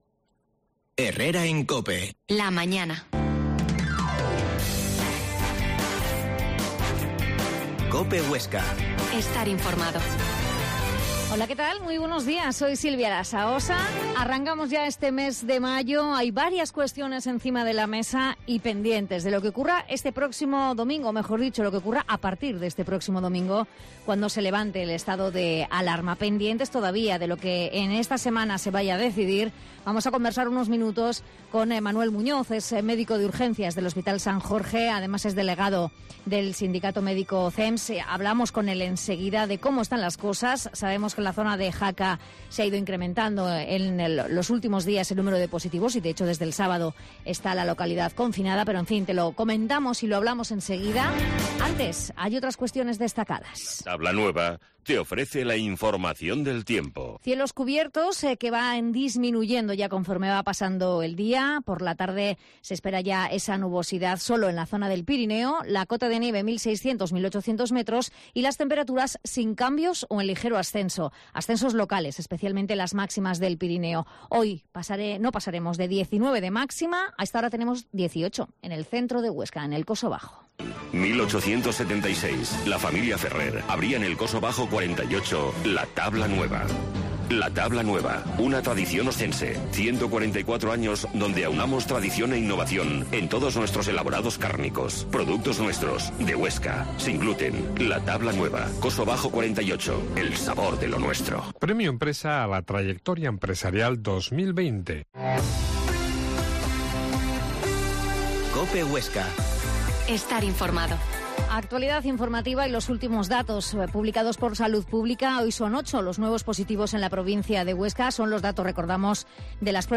La Mañana en COPE Huesca - Informativo local Herrera en Cope Huesca 12,50h. Entrevista